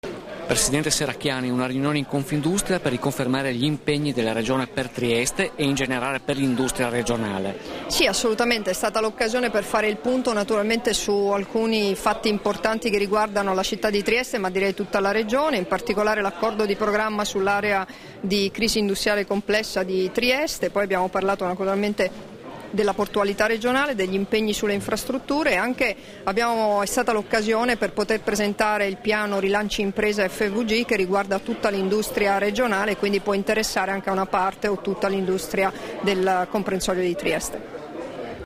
Dichiarazioni di Debora Serracchiani (Formato MP3) rilasciate a margine dell'incontro nella sede di Confindustria, a Trieste il 10 febbraio 2014 [620KB]